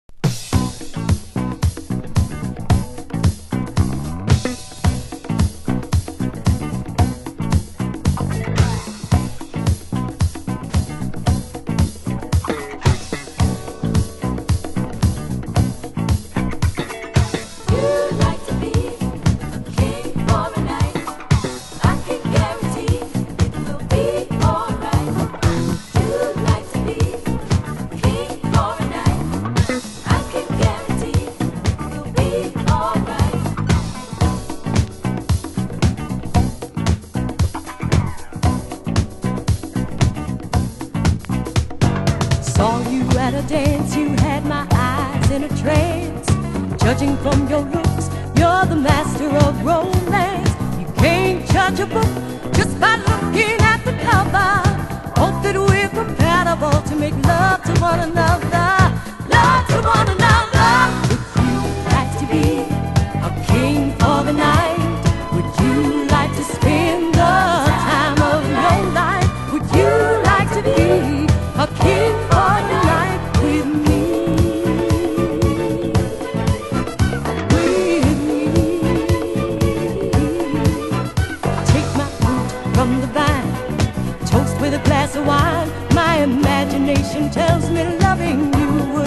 盤質：軽いスレ、小傷、少しチリパチノイズ有/A2中盤にプレスノイズ （小さな凸/試聴後半部）